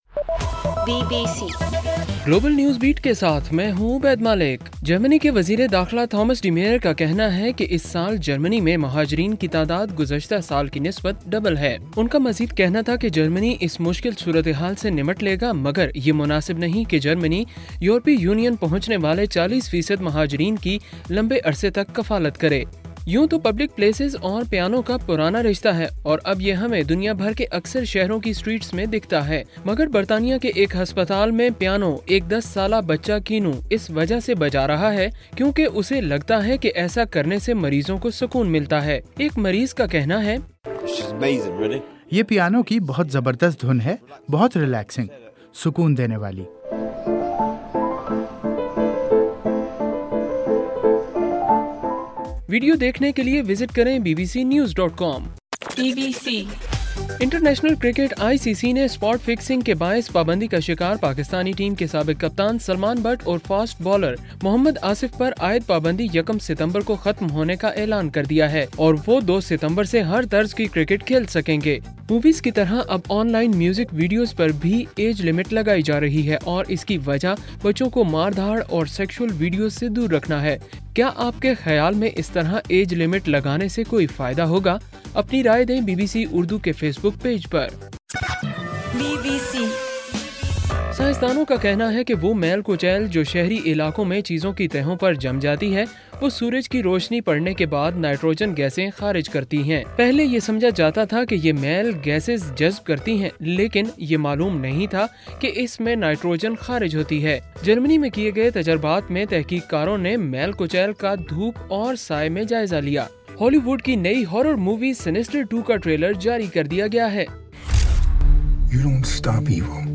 اگست 19: رات 11 بجے کا گلوبل نیوز بیٹ بُلیٹن